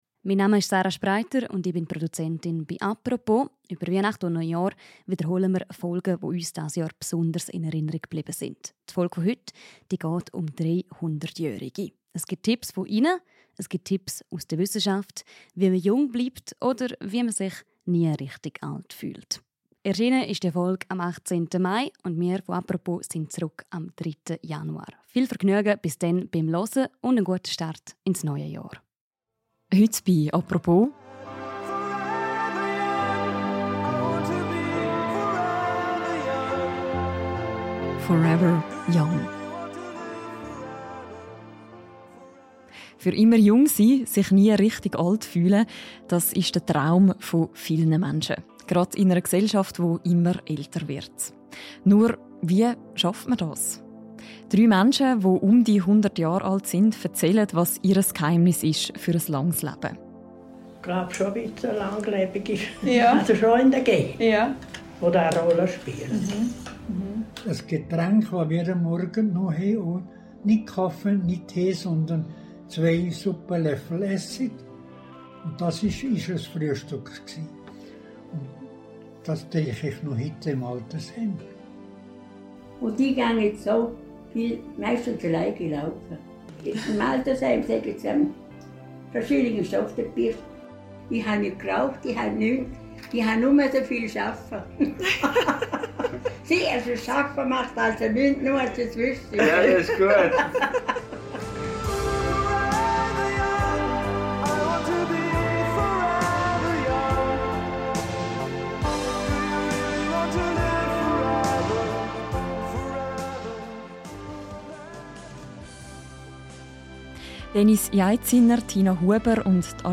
Was braucht es, um das Altern auszubremsen – und sich auch mit vielen Lebenjahren jung zu fühlen? In einer Spezialfolge des täglichen Podcasts «Apropos» erzählen drei Menschen, alle um die 100 Jahre alt, was ihr Geheimnis ist.